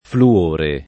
[ flu- 1 re ]